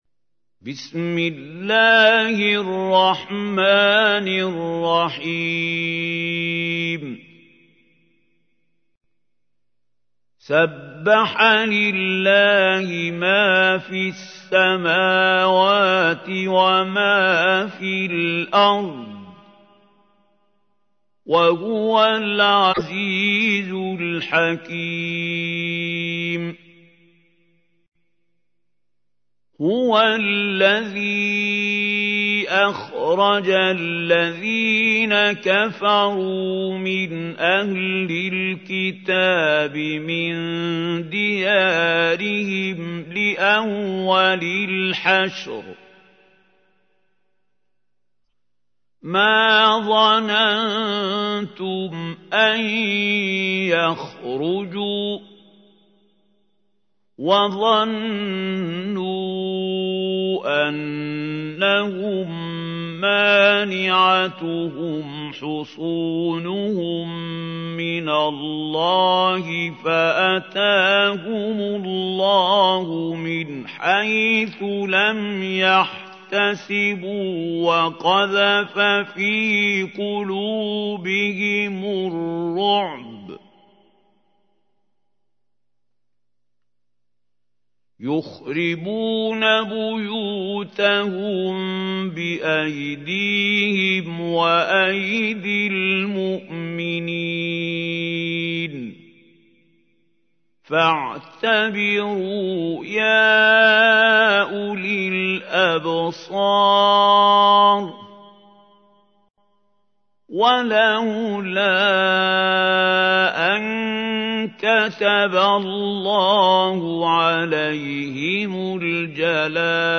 تحميل : 59. سورة الحشر / القارئ محمود خليل الحصري / القرآن الكريم / موقع يا حسين